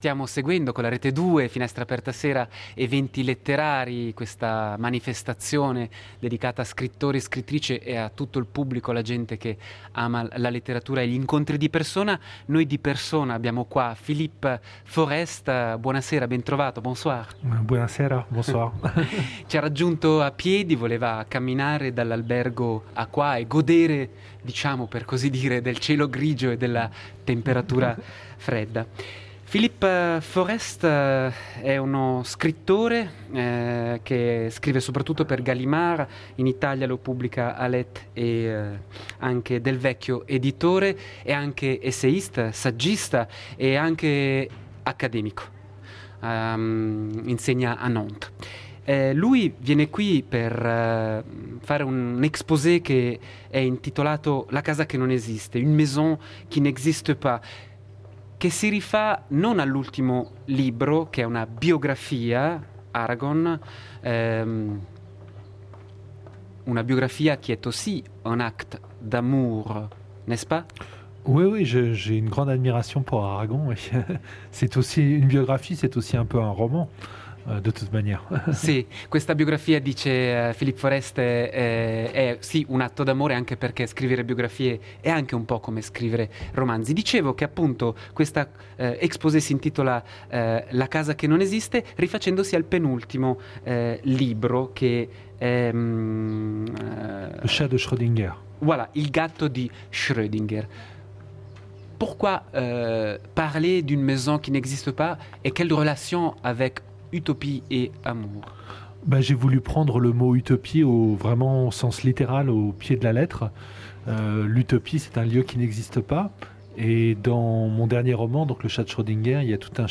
Eventi letterari di Ascona: intervista a Philippe Forest